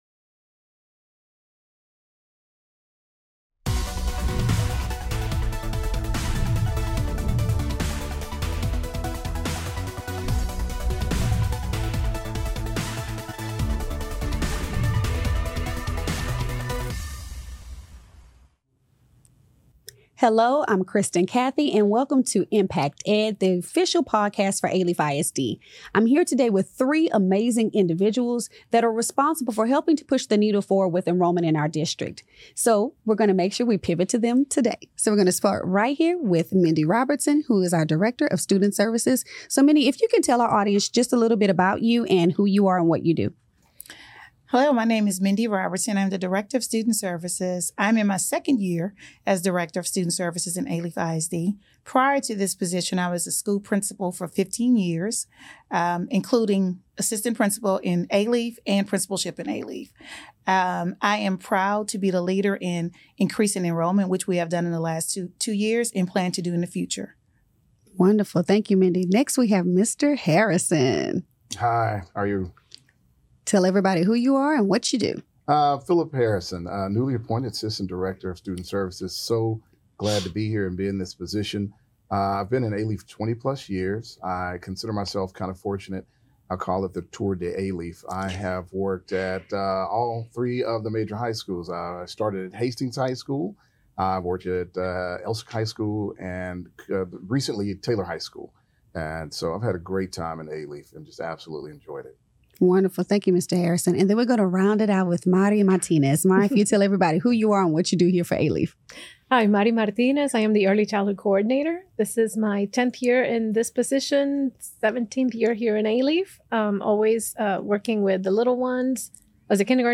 They share valuable insights on the enrollment process, the importance of early education, and how the district supports students and families to ensure a successful start to their academic journey. Tune in for an informative conversation about the vital role enrollment plays in shaping the future of Alief ISD students.